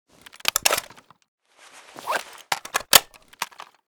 scar_reload.ogg